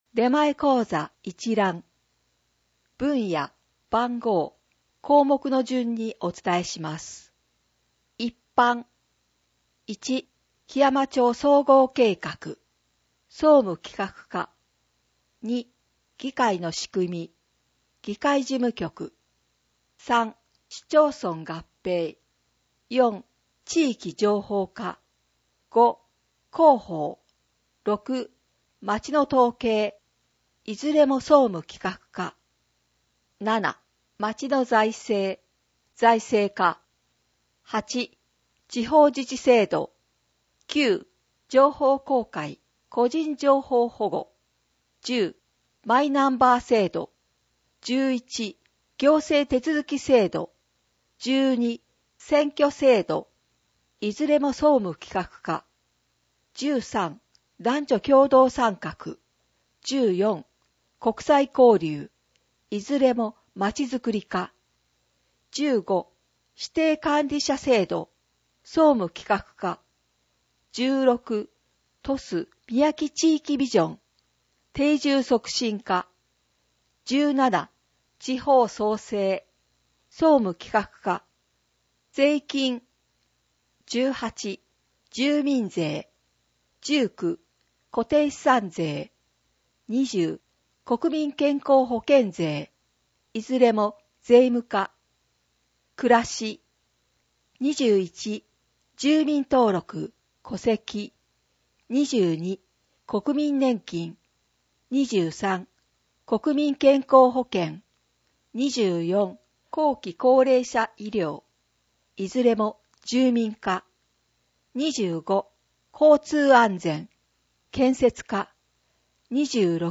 ↑たまに男声も入ります。メリハリがついて、いいですよね。